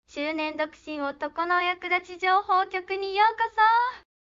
音声生成
音声にしたいことをテキストで入力して、「創作」をクリックします。
今回は、まいやんにしました。
音声生成では、モデルが豊富にあり、自分好みの声質のものを選び、テキスト入力するだけです。